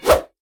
fire1.ogg